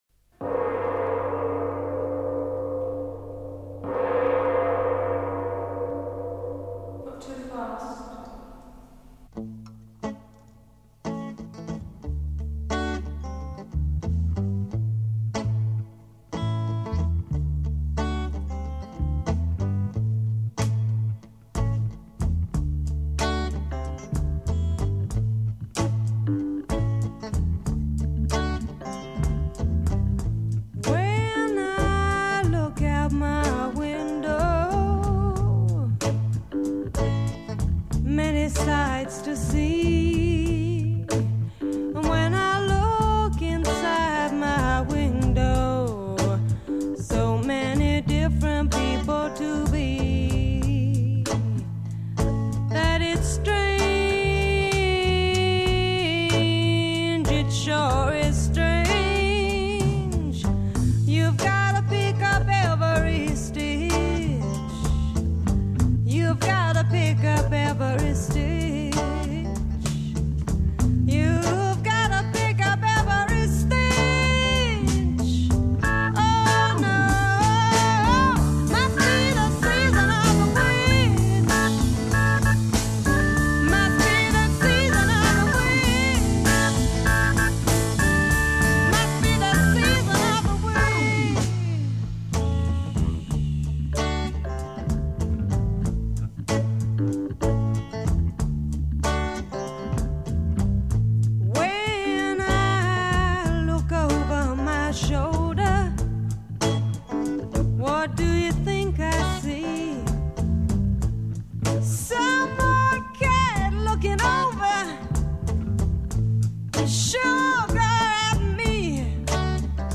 Recorded at Chappell's Studios, London, 1967.
prelude 0:00 gong and spoken aside
intro   guitar, add bass, add drum & high-hat, add organ